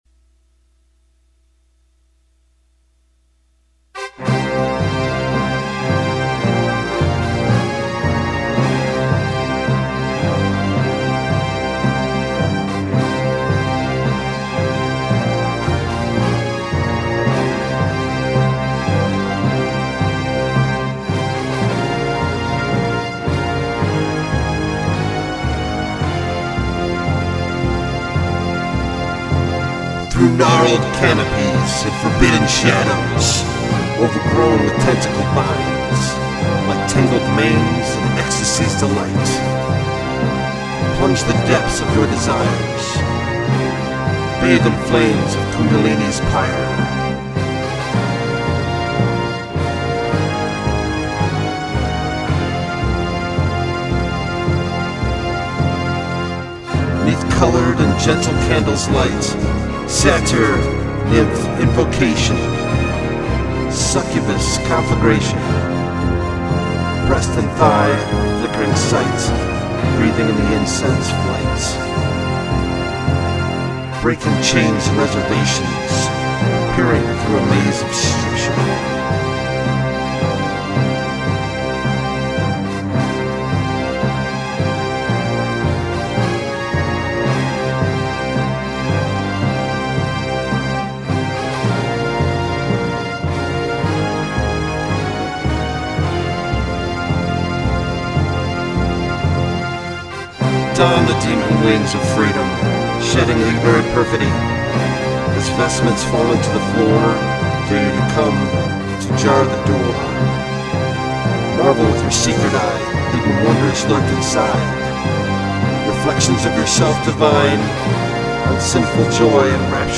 | Instrumental From Los Angeles, CA